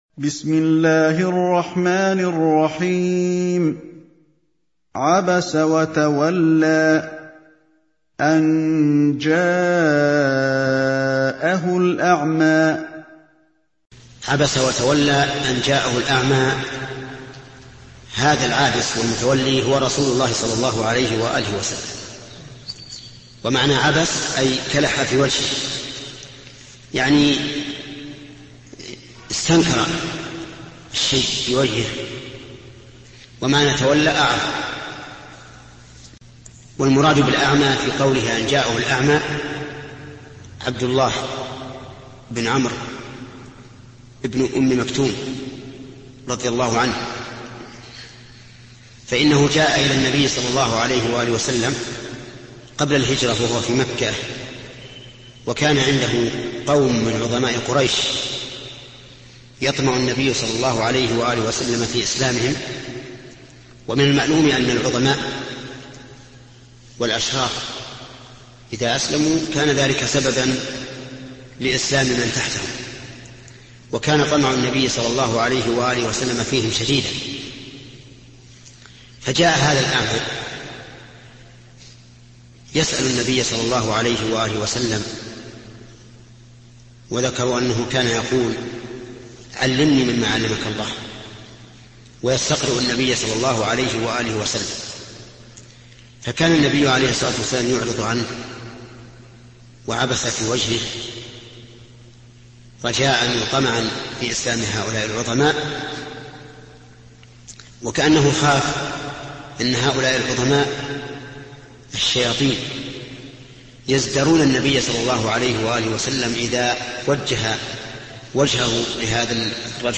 الدرس الرابع: تفسير سورة عبس: من قوله: (تفسير سورة عبس)، إلى: نهاية تفسير سورة عبس.